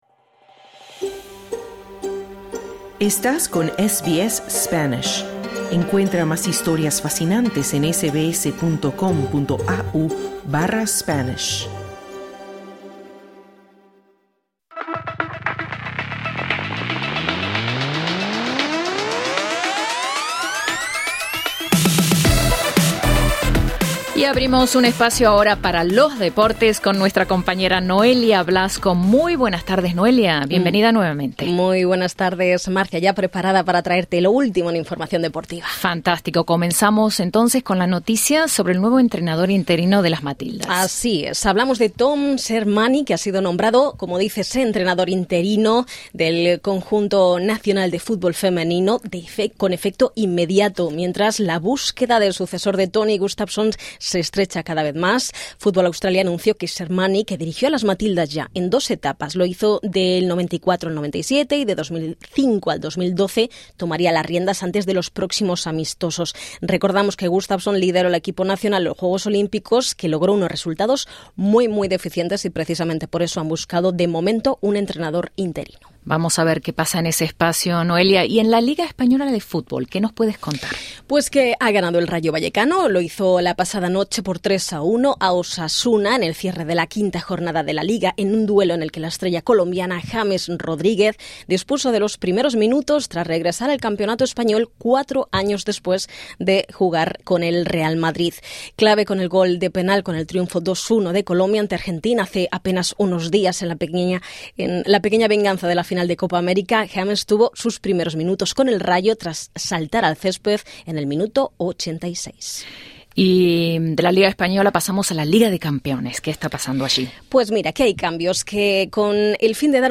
Escucha el informe deportivo en el podcast localizado en la parte superior de esta página.